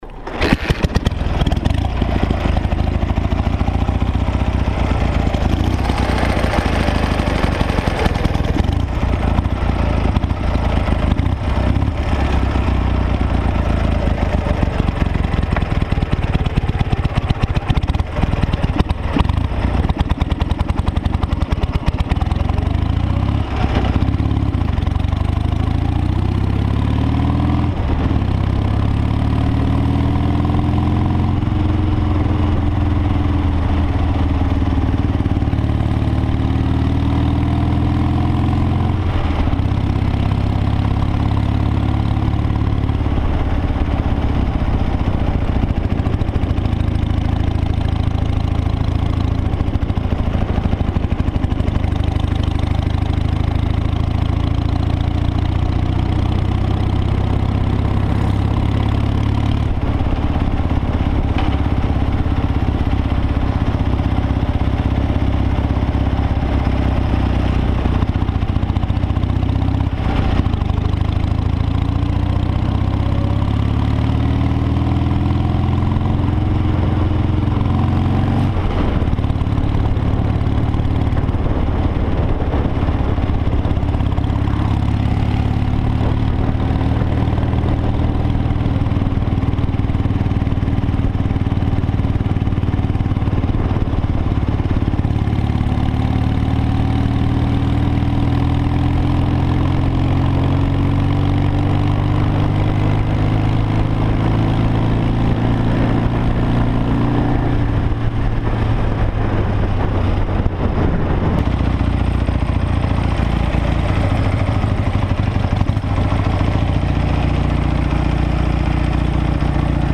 Звуки мотоциклов
Звук мотоцикла Днепр 11 в движении